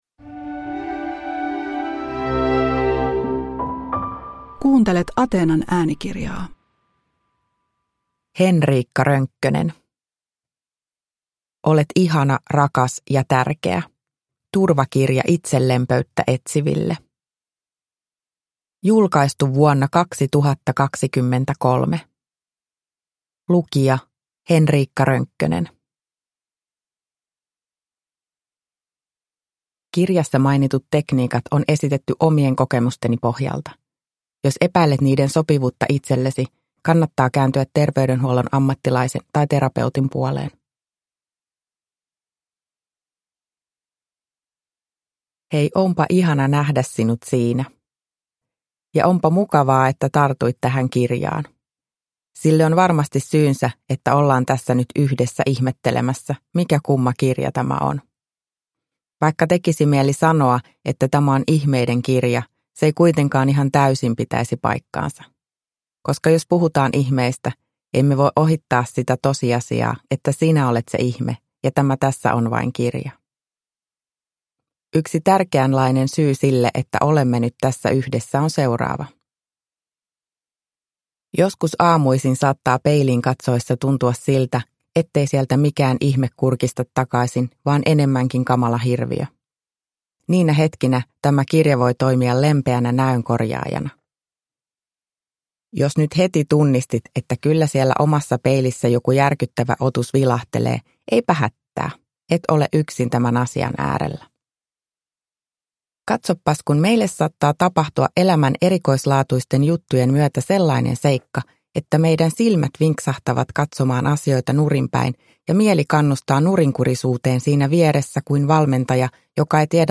Olet ihana, rakas ja tärkeä – Ljudbok